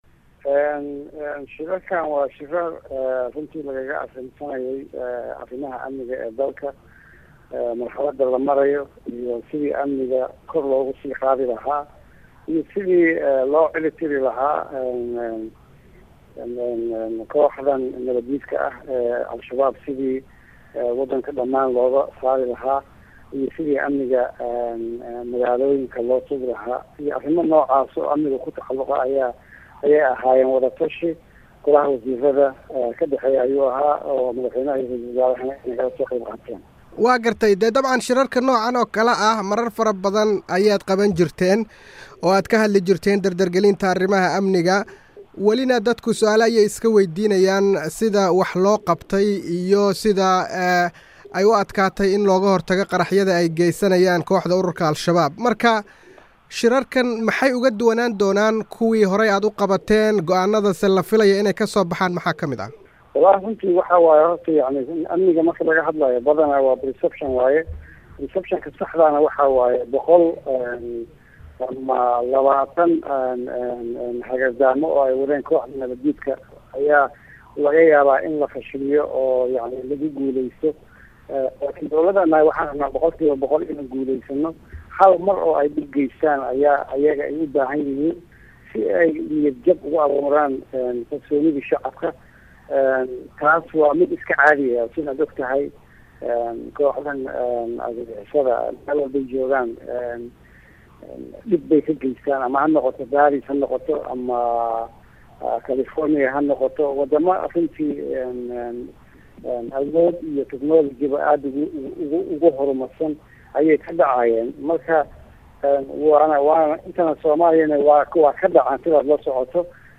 Wareysi: Wasiirka amniga qaranka